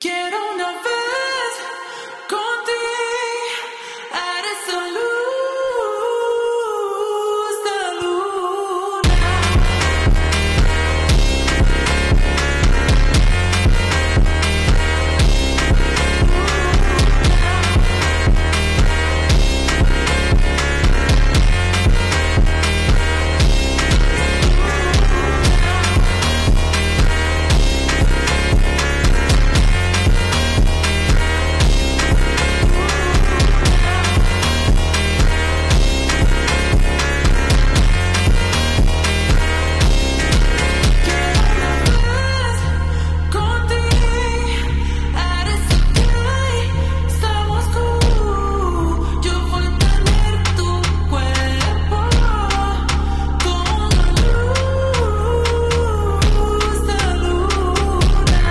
French commentary in football 🇫🇷🔥☠